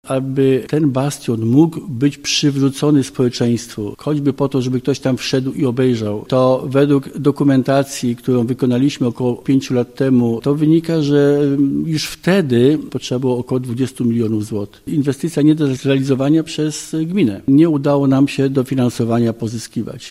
Problemem są oczywiście pieniądze. Suma potrzebna do uratowania bastionu jest ogromna, przekraczająca możliwości Kostrzyna nad Odrą. – Wydeptujemy wszystkie ścieżki, aby zdobyć środki, ale od lat udaje nam się jedynie zapobiegać, a nie leczyć – mówi Andrzej Kunt, burmistrz Kostrzyna nad Odrą: